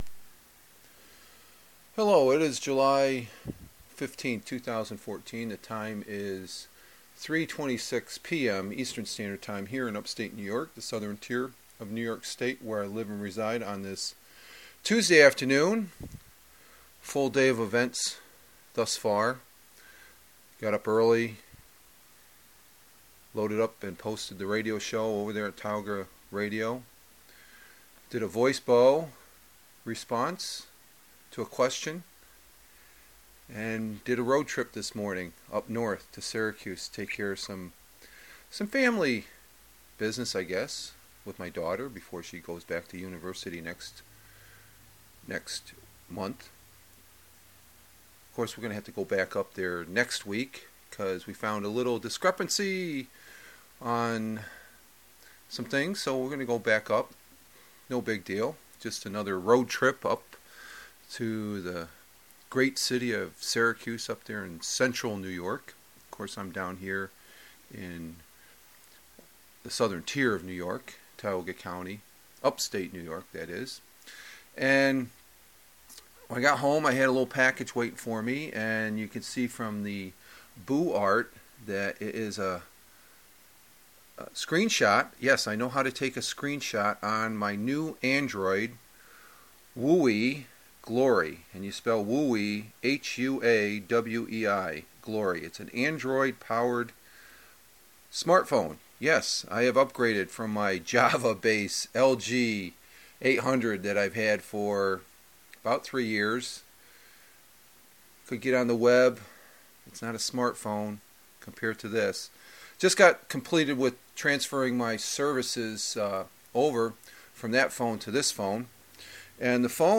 Very first Audiobook using my new Huawei Glory TracFone, with Android 4.0.4 Ice Cream Sandwich.